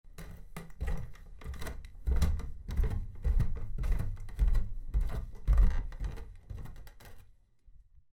Creaky.ogg